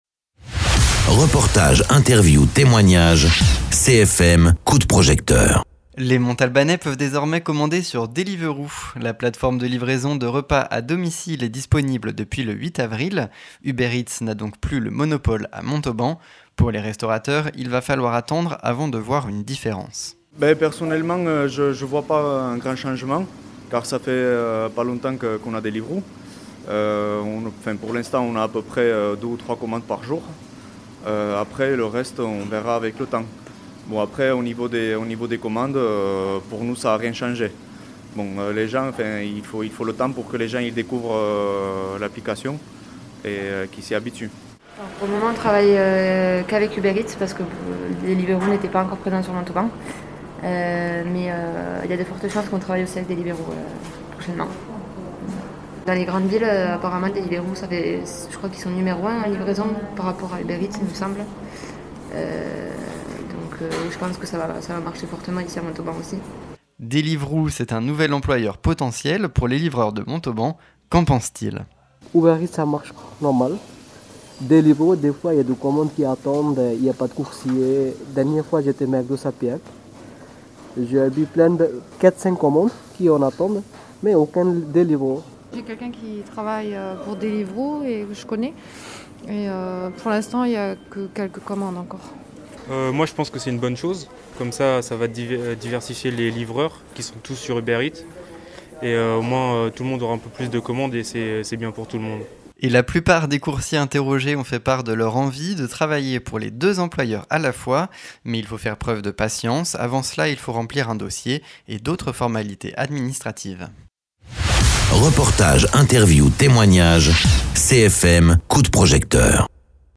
Invité(s) : Restaurateurs et livreurs montalbanais